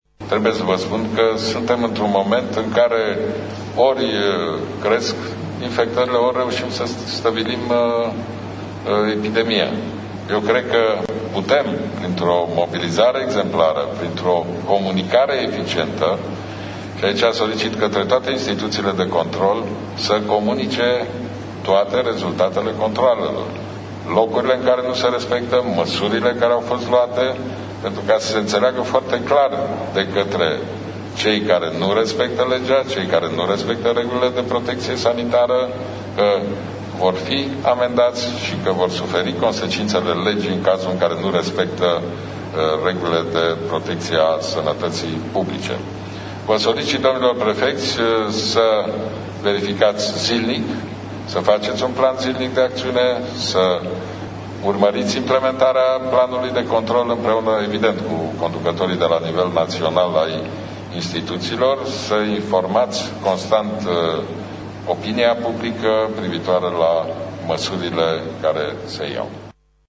Premierul Ludovic Orban le-a cerut prefecţilor şi şefilor de instituţii cu atribuţii în combaterea pandemiei de COVID-19 să amplifice controalele, pentru a asigura respectarea regulilor de protecţie a sănătăţii oamenilor: